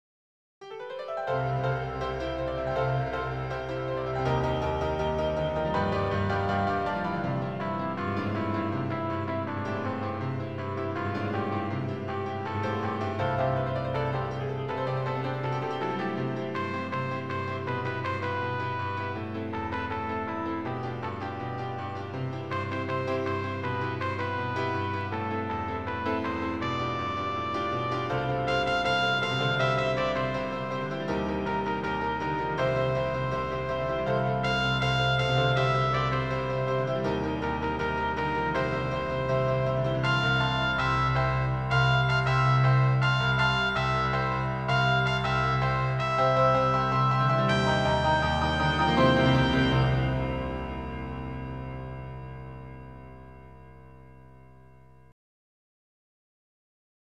挿入歌５